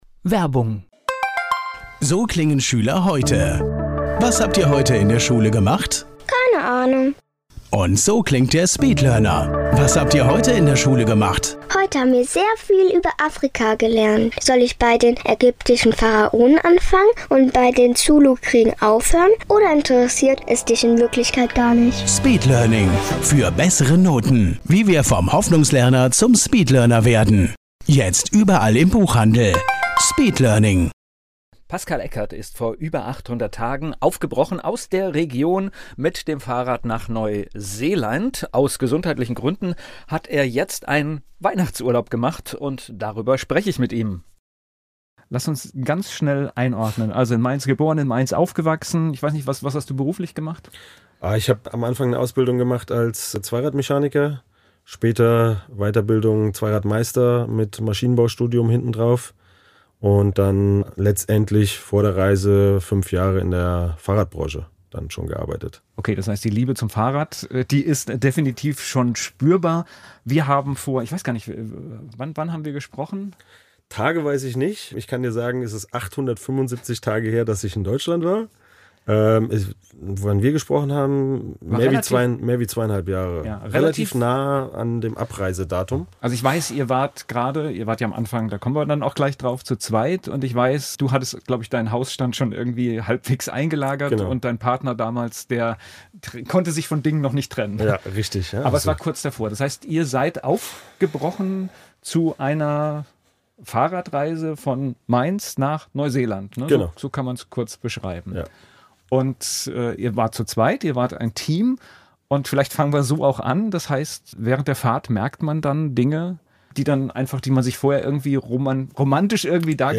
Ein ehrliches, intensives und inspirierendes Gespräch über Mut, Menschlichkeit und die Frage, was wirklich zählt, wenn man monatelang nur mit dem lebt, was auf ein Fahrrad passt. https